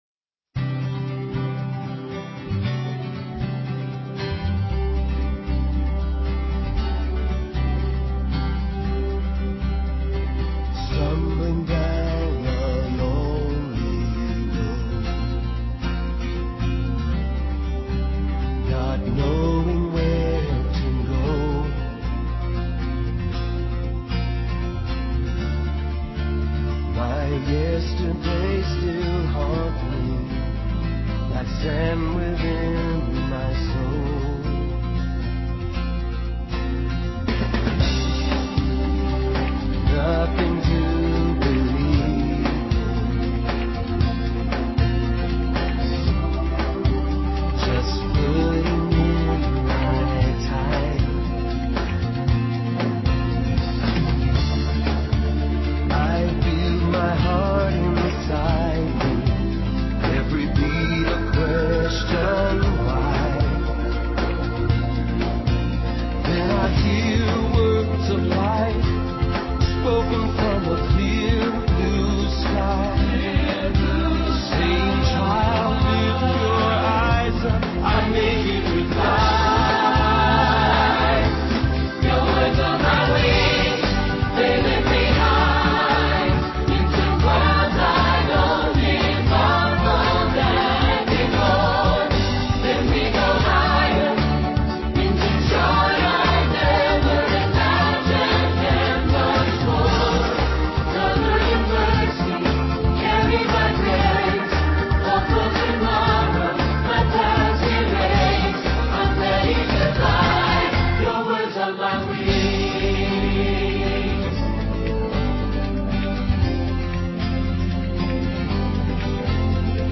PLAY: Marriage & Divorce: What Jesus Taught, May 16 & 23, 2010 Scripture: Matthew 19:3-12; 22:23-32. Messages have been rerecorded, due to technical difficulties experienced in recording the May 16 and May 23 worship services. Music has been added (not a part of the original worship services.).